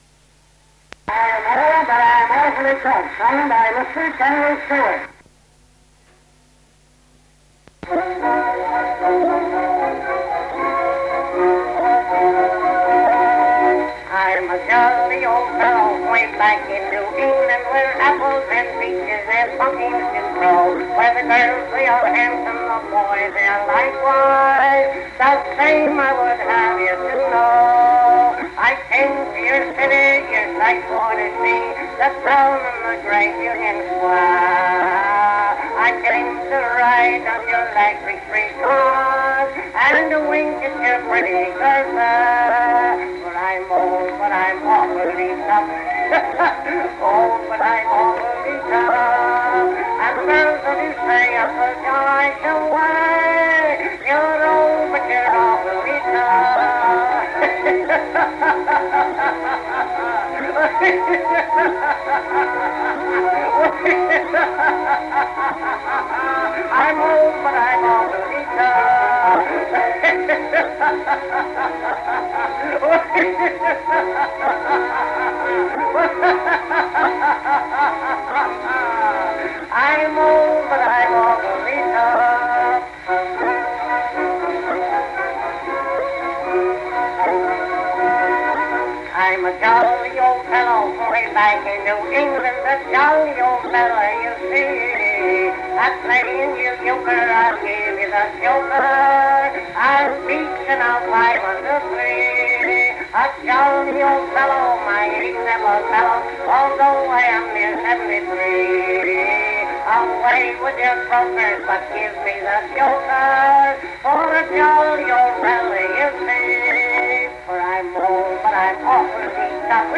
American wit and humor
Music (performing arts genre)